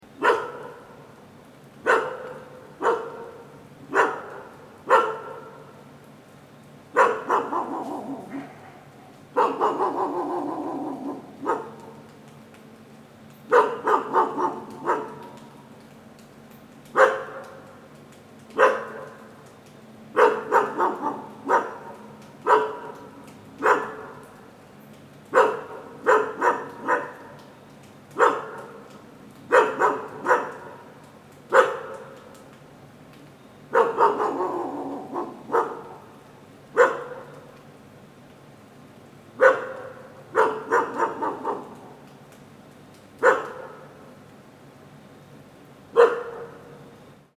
دانلود آهنگ سگ در خیابان از افکت صوتی انسان و موجودات زنده
دانلود صدای سگ در خیابان از ساعد نیوز با لینک مستقیم و کیفیت بالا
جلوه های صوتی